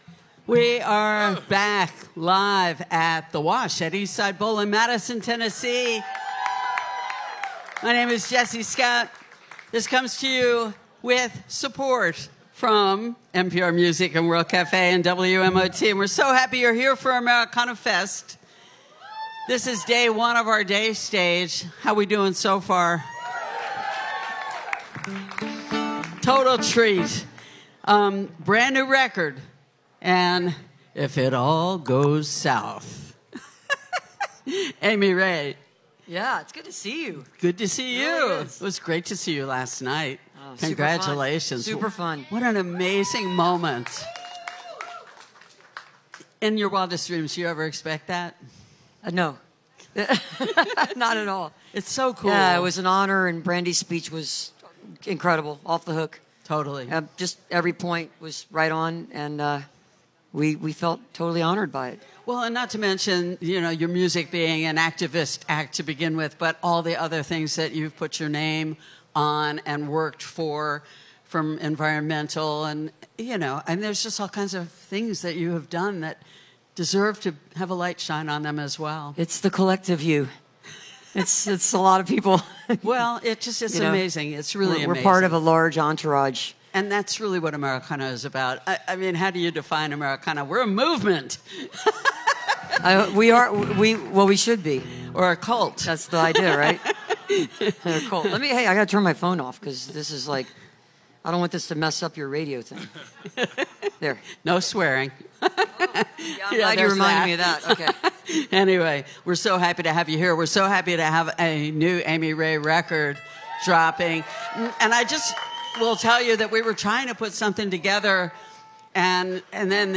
lifeblood: bootlegs: 2022-09-15: the wash at eastside bowl - nashville tennessee (americanafest day stage) (amy ray)
(audio capture from a facebook live stream)